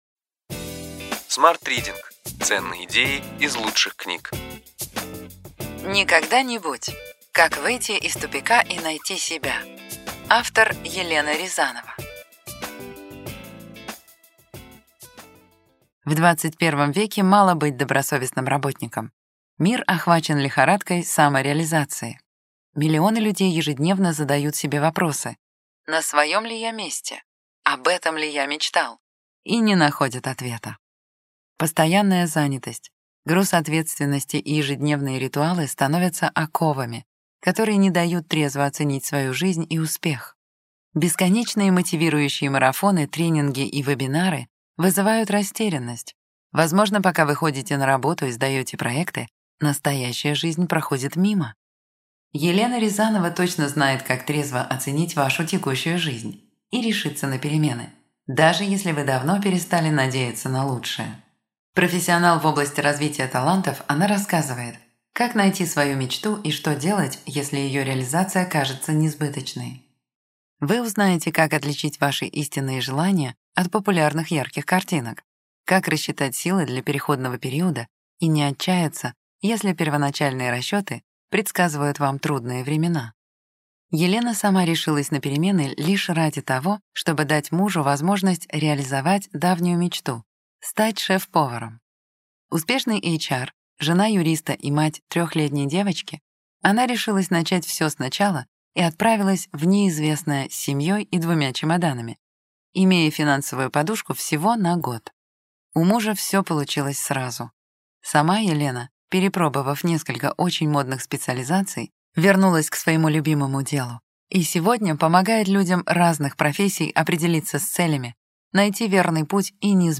Аудиокнига Ключевые идеи книги: Никогда-нибудь. Как выйти из тупика и найти себя.